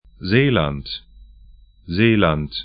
Aussprache
Seeland 'ze:lant Zeeland 'ze:lant nl Gebiet / region 51°27'N, 03°45'E